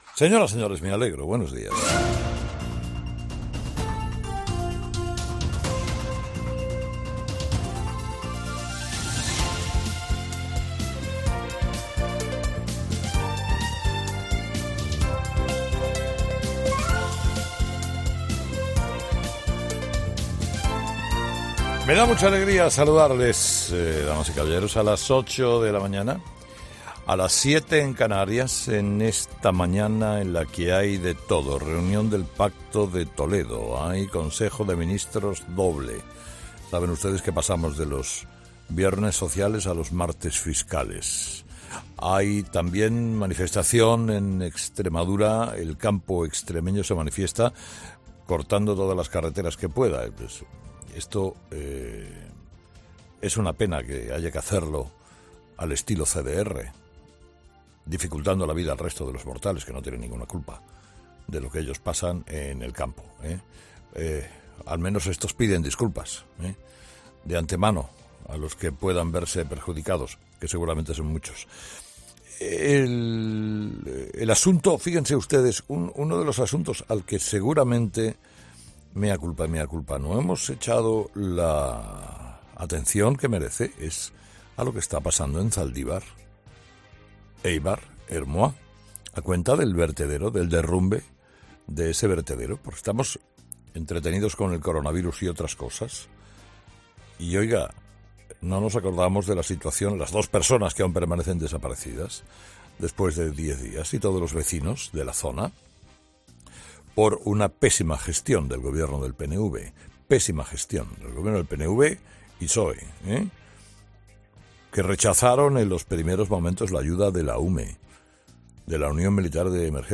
Destacamos este monólogo de Herrera entre lo mejor de Herrera en COPE